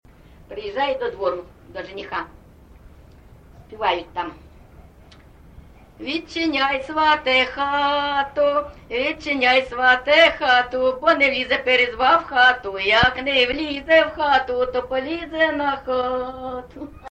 ЖанрВесільні
Місце записум. Маріуполь, Донецька обл., Україна, Північне Причорноморʼя